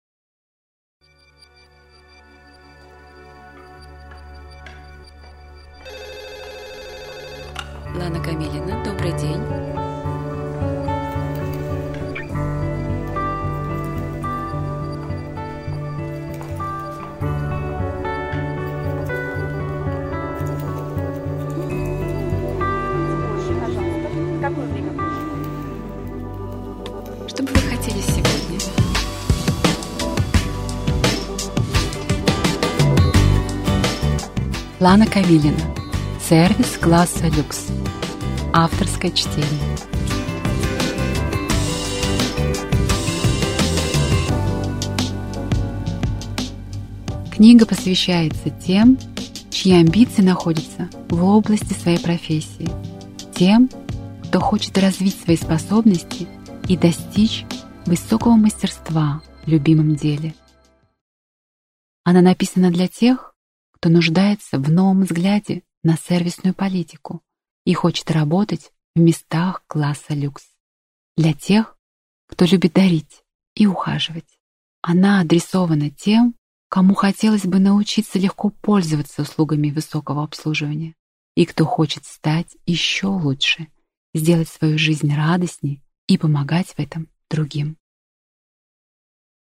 Аудиокнига Сервис класса люкс. Розовая книга менеджера | Библиотека аудиокниг